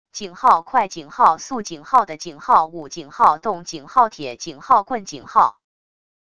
#快#速#的#舞#动#铁#棍#wav音频